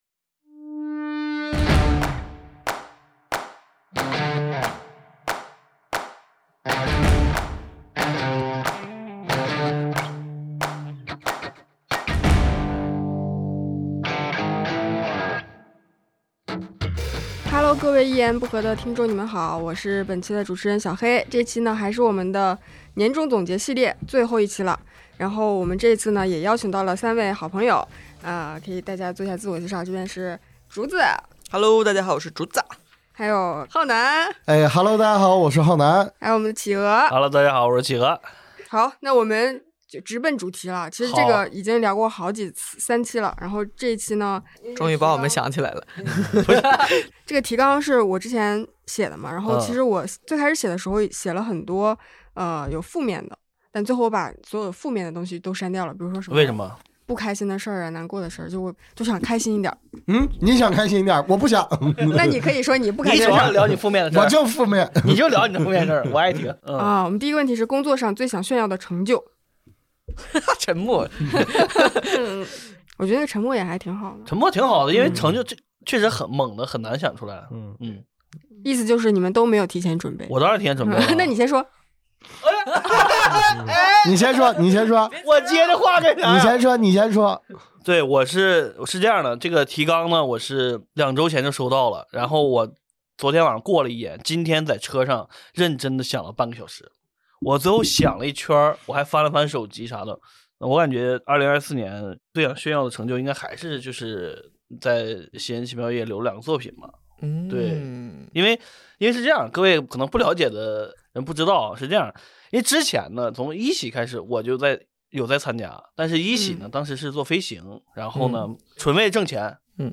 本期节目录制于在阿那亚举办的单立人喜剧节期间。